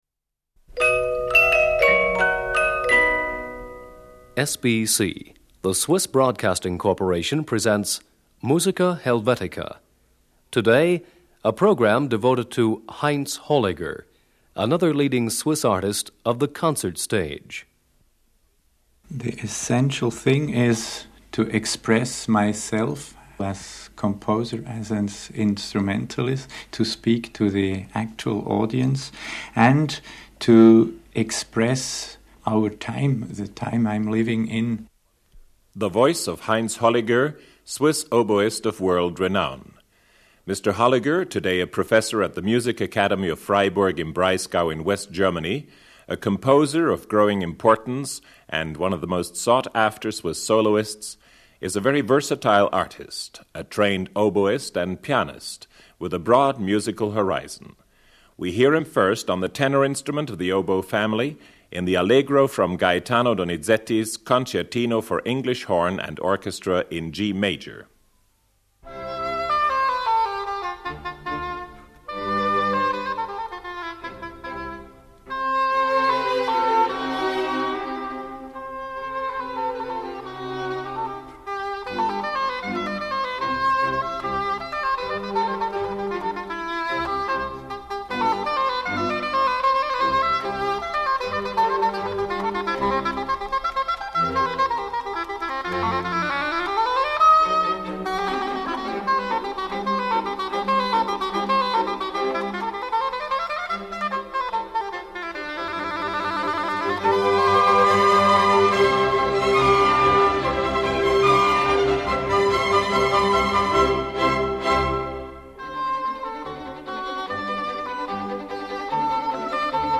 From Concertino for English Horn and Orchestra in G major.
english horn.
bassoon.
harpischord.